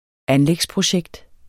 Udtale [ ˈanlεgs- ]